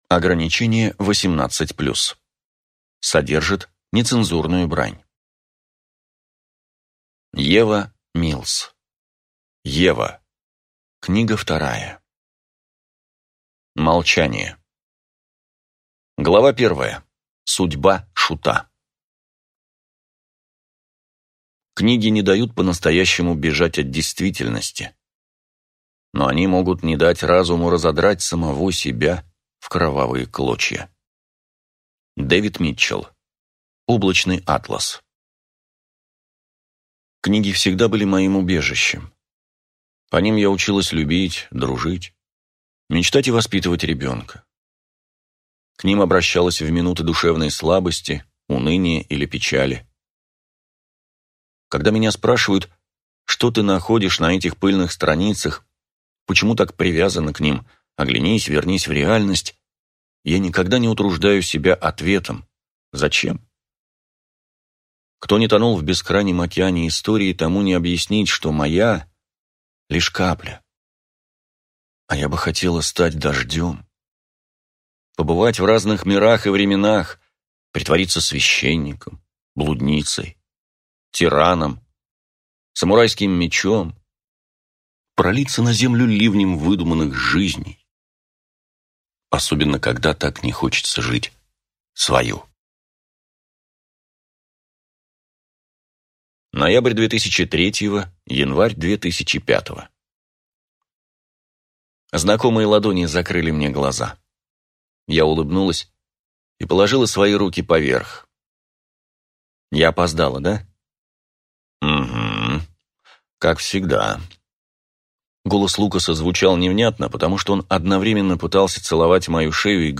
Аудиокнига Ева. Книга 2 | Библиотека аудиокниг